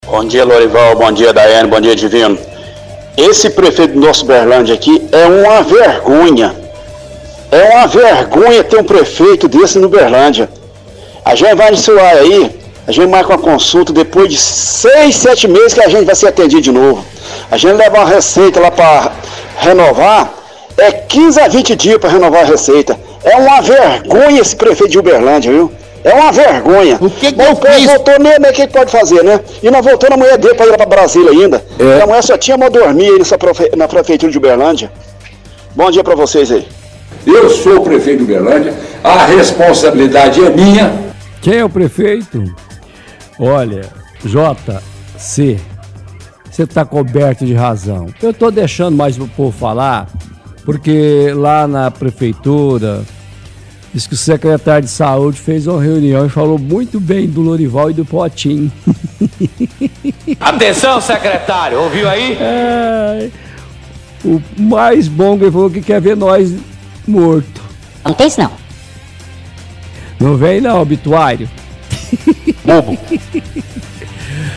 -Ouvinte diz que prefeito de Uberlândia é uma vergonha. Reclama de consultas nas UAIs que demoram e trocas de receitas também demora.